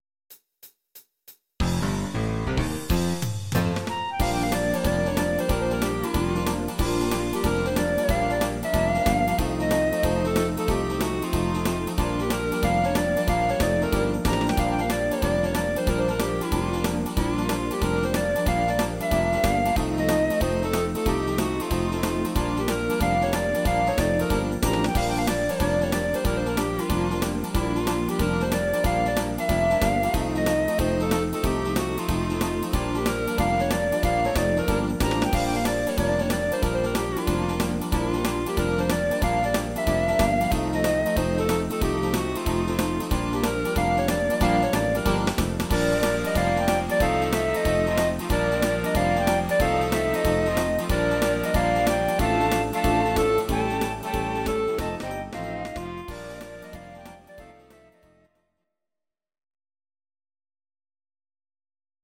These are MP3 versions of our MIDI file catalogue.
Please note: no vocals and no karaoke included.
Klarinette